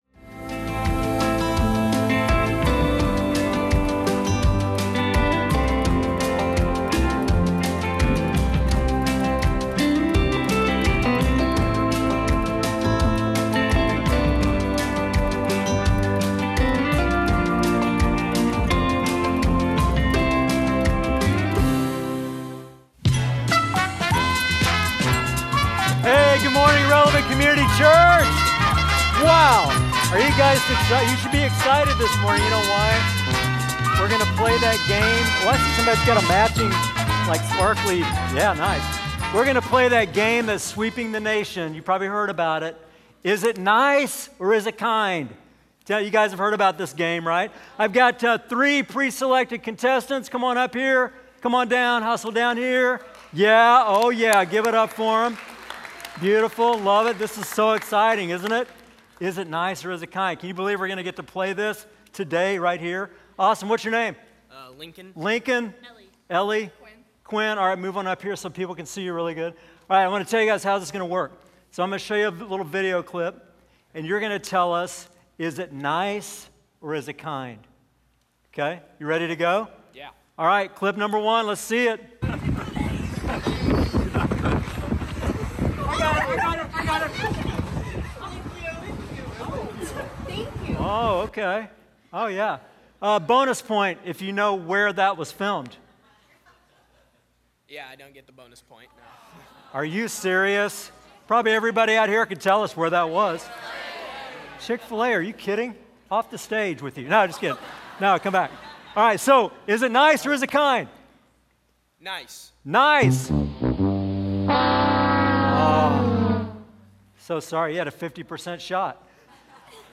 Sunday Sermons FruitFULL, Week 5: "Kindness" Feb 22 2026 | 00:31:10 1x 00:00 / 00:31:10 Subscribe Share Apple Podcasts Spotify Overcast RSS Feed Share Link Embed